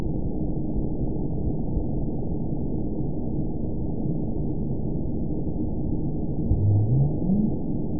event 914408 date 05/07/22 time 01:57:56 GMT (3 years ago) score 9.41 location TSS-AB01 detected by nrw target species NRW annotations +NRW Spectrogram: Frequency (kHz) vs. Time (s) audio not available .wav